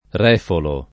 r$folo] (meno com. rifolo) s. m. — es. con acc. scr.: a colpi, a buffi, a rìfoli, a ràffiche [a kk1lpi, a bb2ffi, a rr&foli, a rr#ffike] (D’Annunzio); freschi rèfoli di brezza barbarica [fr%Ski r$foli di br%zza barb#rika] (Papini) — Refolo anche cognome